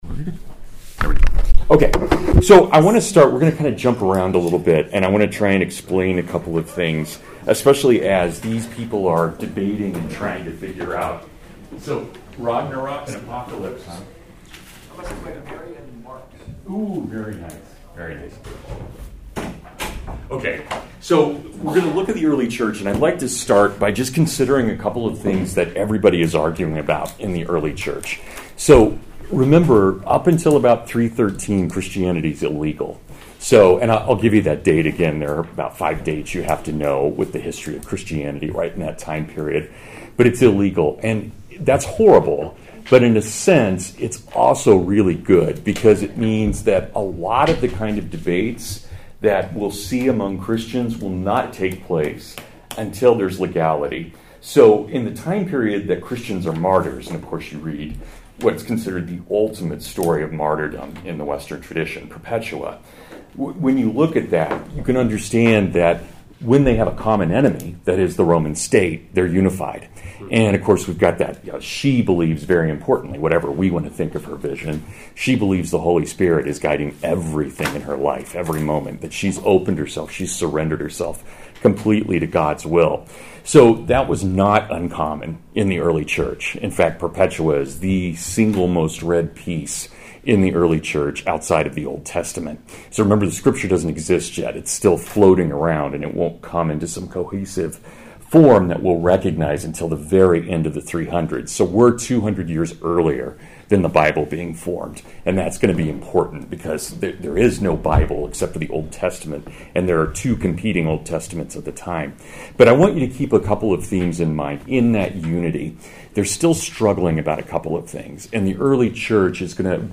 Scripture and Martyrdom in the Early Church (Full Lecture)